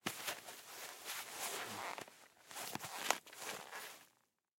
Скрип сжатых кожаных перчаток